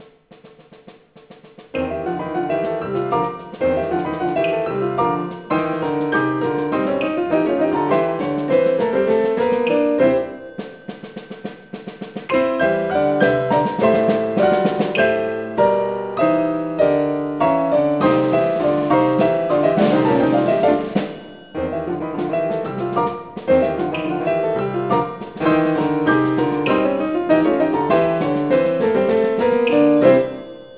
piano = klavier
percussion
for two pianos and percussion